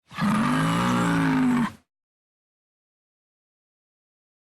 sfx_angry_bull_beginning_or_halfway.opus